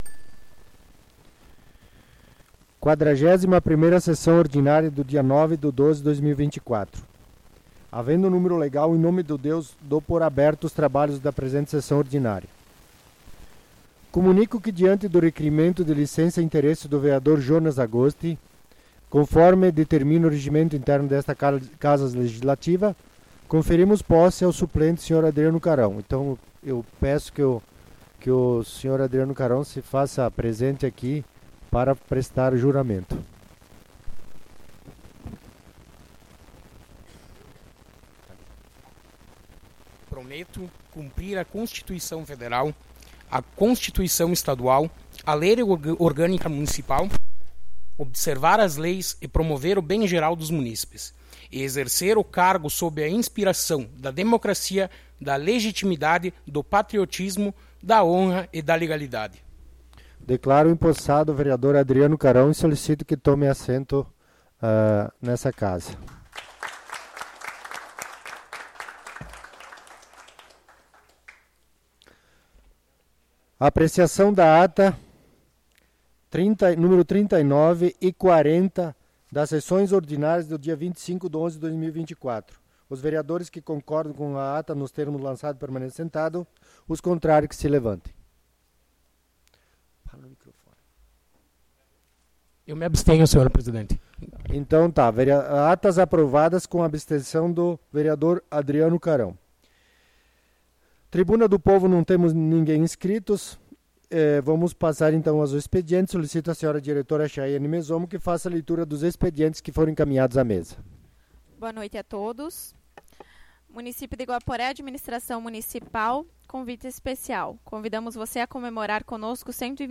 Sessão Ordinária do dia 09 de Dezembro de 2024